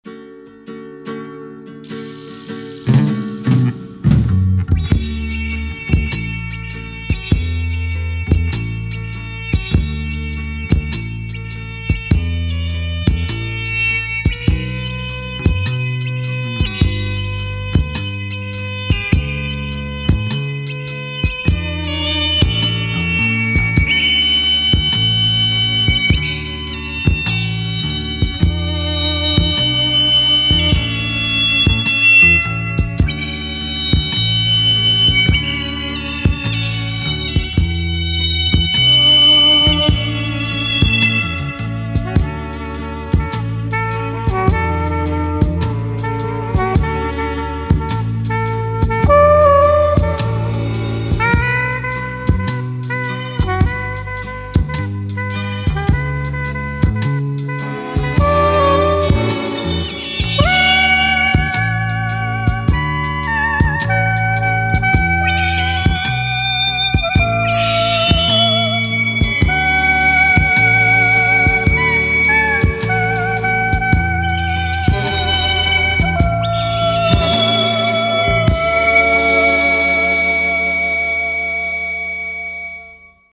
Children's tune